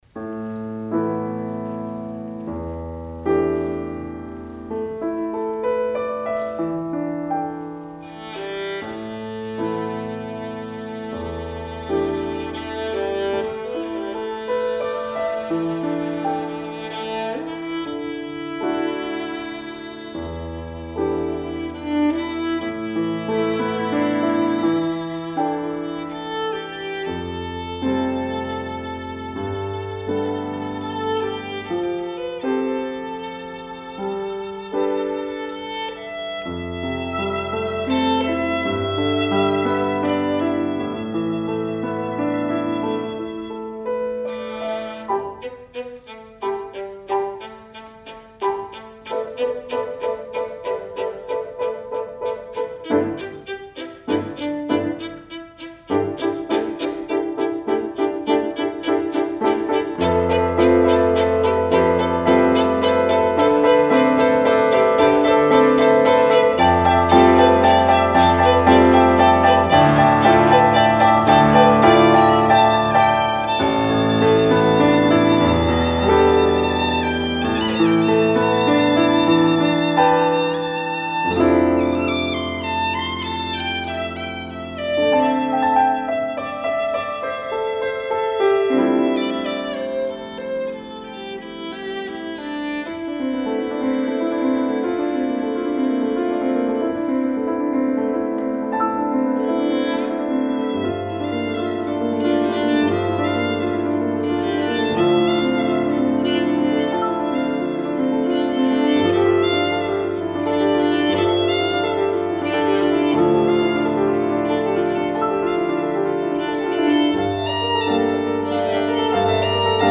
for Violin and Piano (2016)